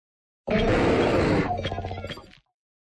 Cri de Roc-de-Fer dans Pokémon Écarlate et Violet.